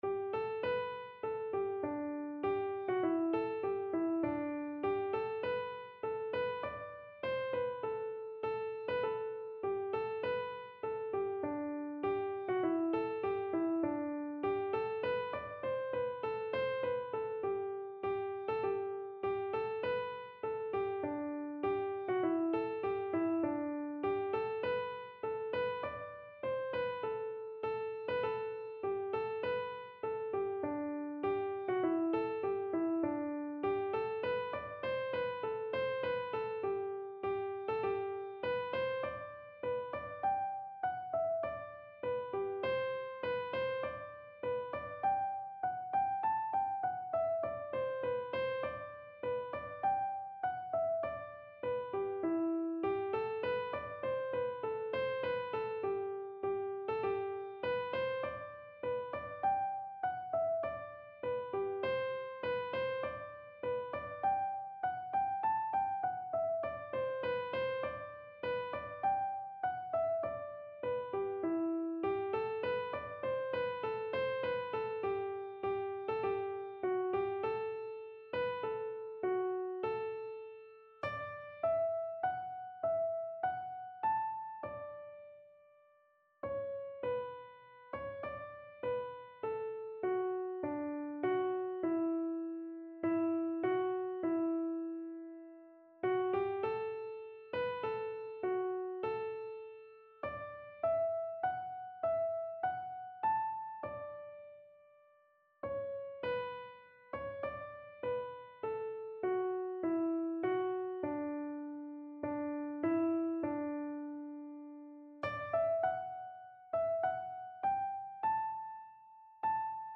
Click any page number to hear the tunes on that page, played slowly so you can learn them.